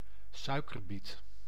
Ääntäminen
Tuntematon aksentti: IPA: /ˈsœy̯kərˌbit/